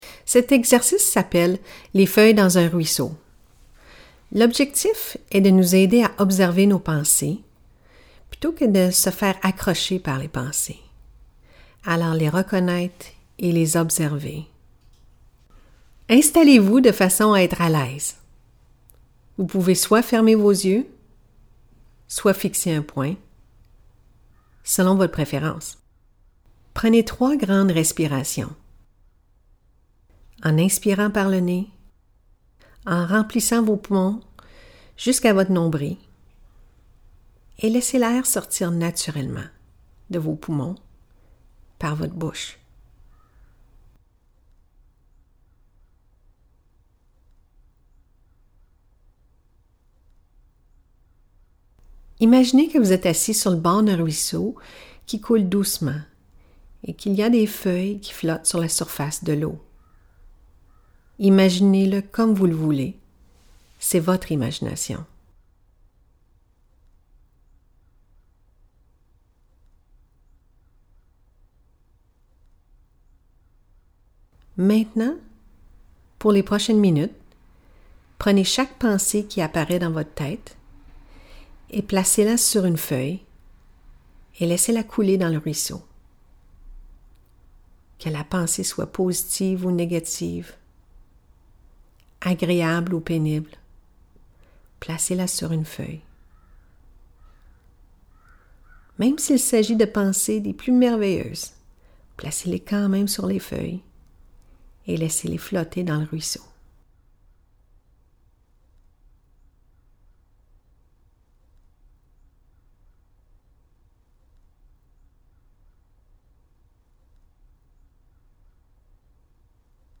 Nos enregistrements audio sont effectués au studio Neptunes Music.